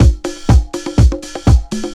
C5HOUSE123.wav